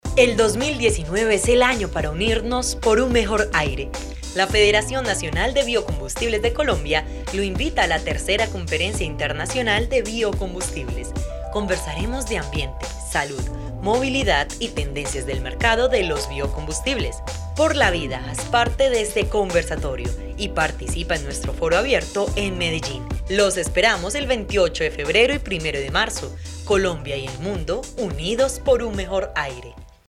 A soft voice with the kindness of a friend and the strength of a professional, from Colombia.
Sprechprobe: Industrie (Muttersprache):
Fresh, calm, sweet and deep when is needed.
Locución comercial_Biocombustibles.mp3